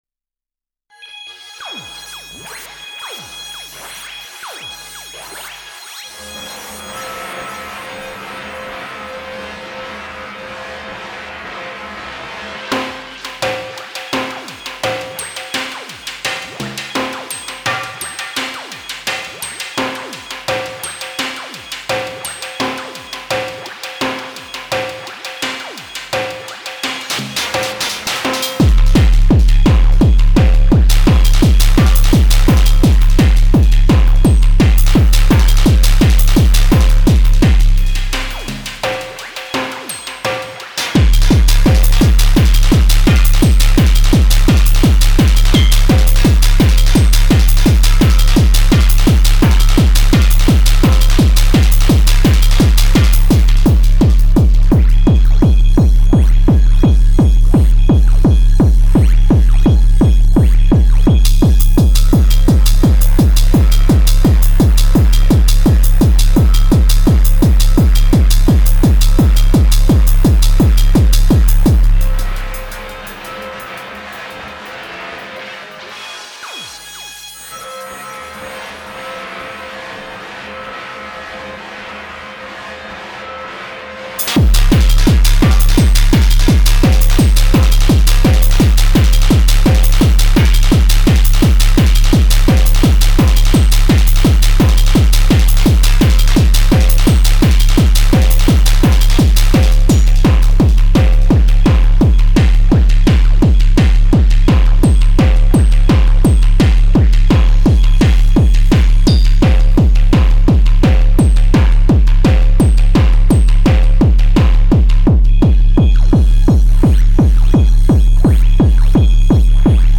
Digitone (DN+DT) only music
Less subtle, max overdrive test !